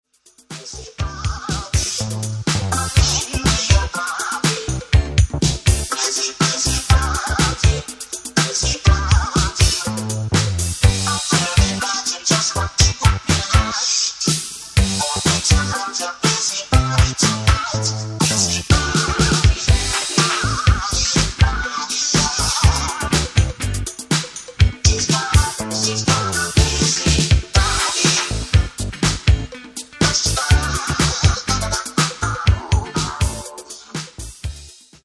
Genere:   Disco | Funk